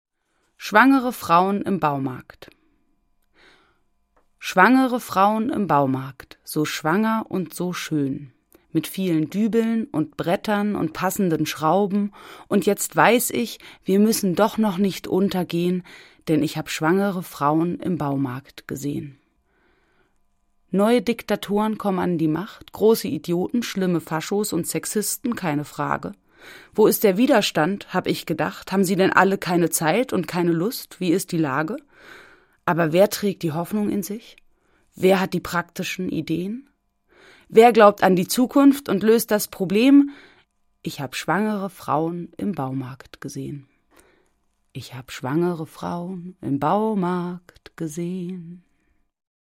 Das radio3-Gedicht der Woche: Dichter von heute lesen radiophone Lyrik. Und vermitteln unseren Hörern damit eine Wochenration Reim, Rhythmus und Wohlklang.